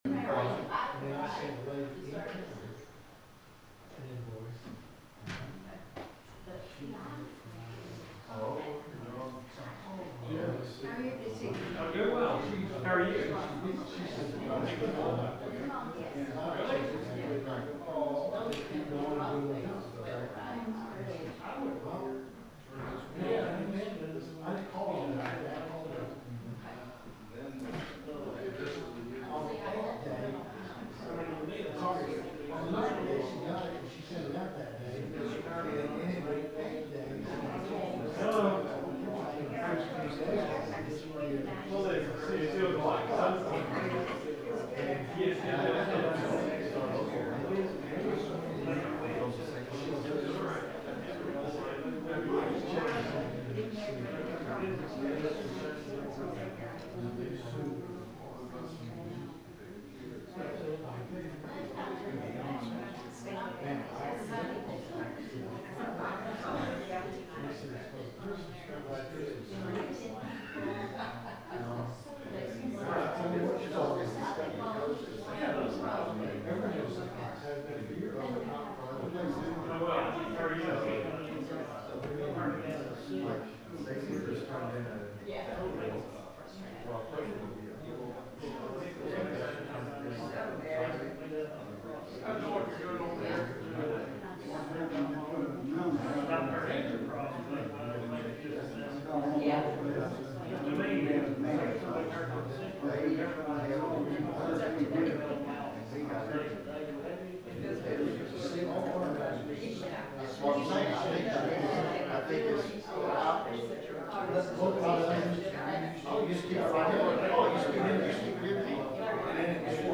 The sermon is from our live stream on 2/18/2026